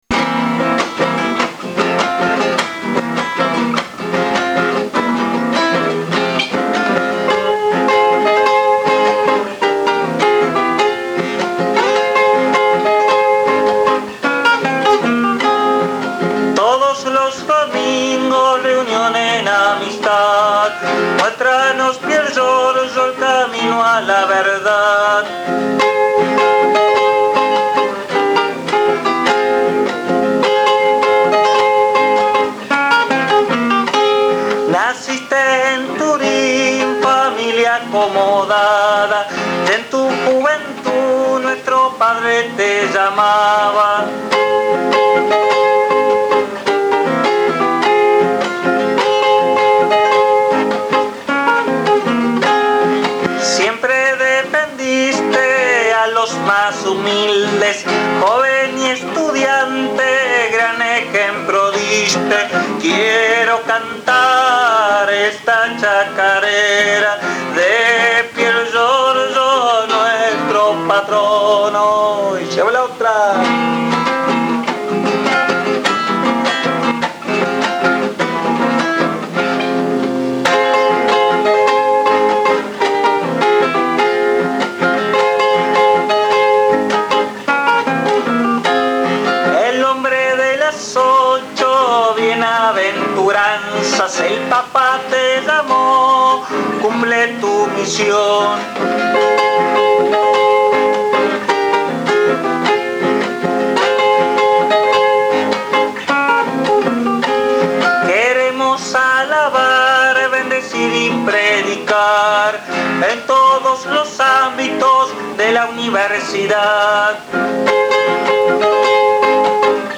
HISTORIA DE LA CHACARERA